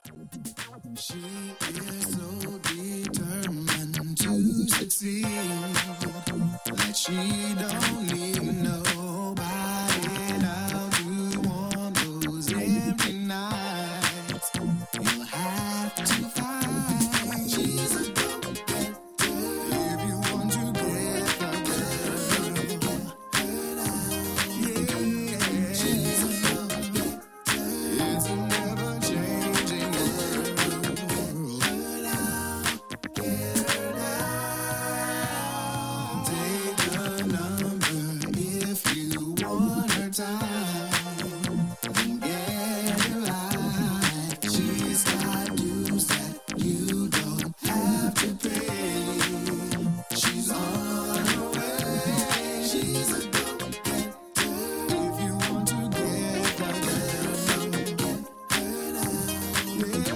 a pair of funk covers